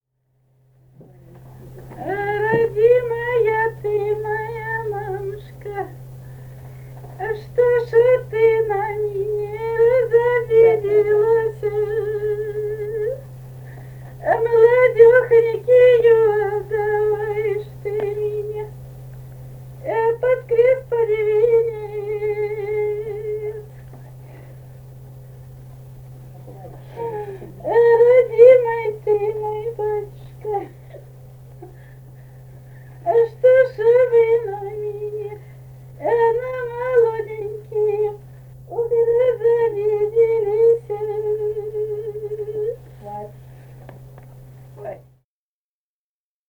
полевые материалы
«А родимая ты моя мамушка» (свадебное причитание).
Самарская область, с. Усманка Борского района, 1972 г. И1316-21